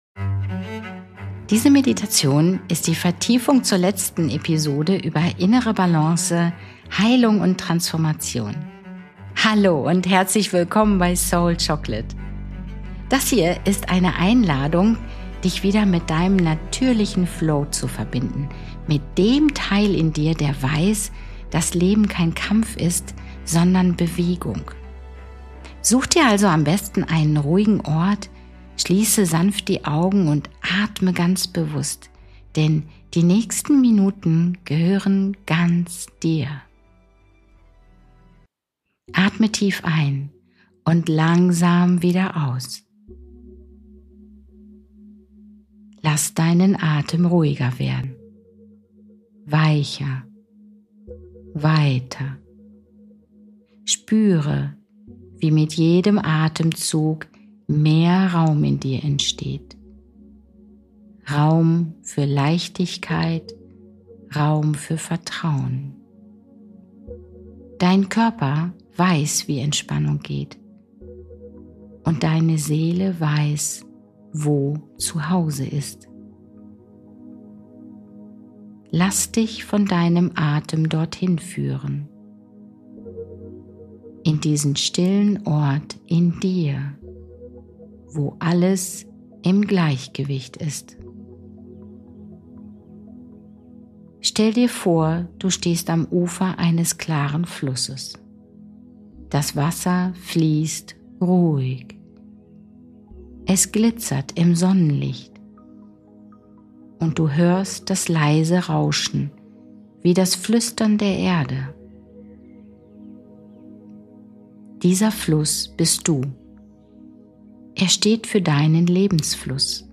Diese geführte Soul-Chocolate-Meditation begleitet dich in deinen